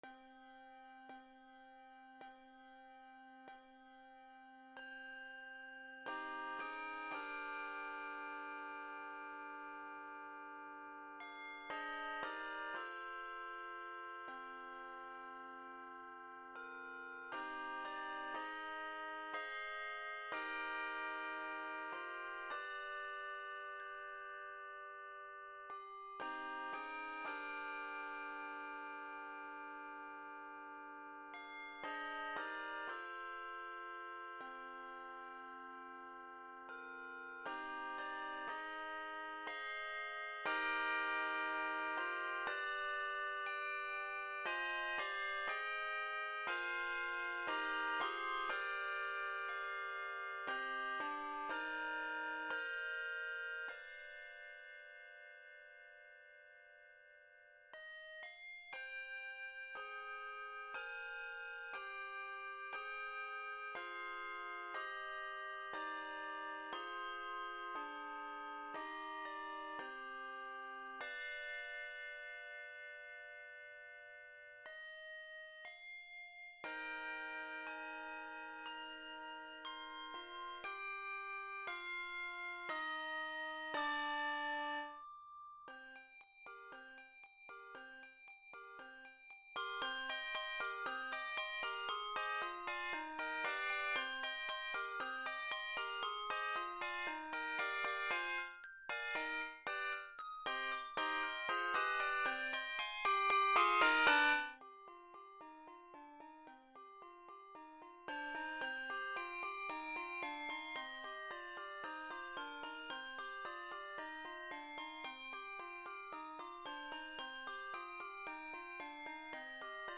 Scored in c minor, this piece is 67 measures.